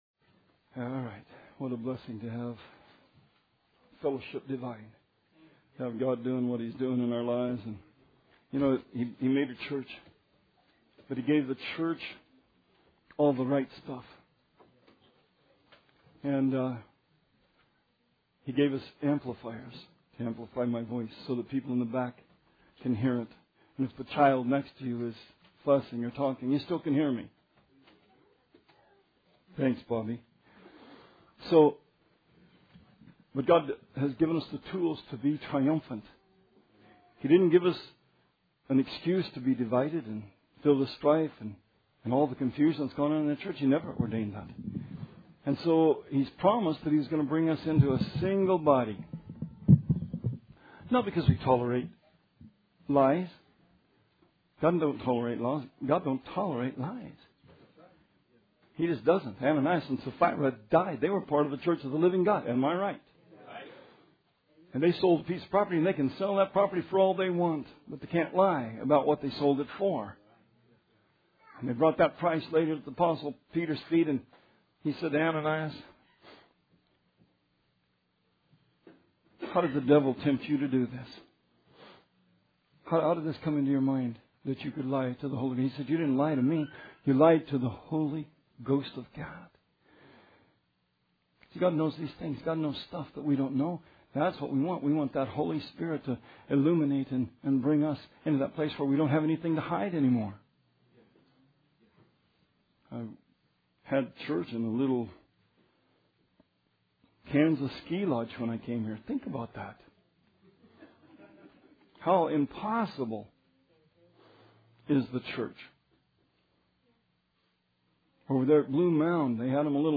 Sermon 1/24/16 – RR Archives